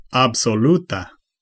Ääntäminen
IPA : /ˈpɑz.ə.tɪv/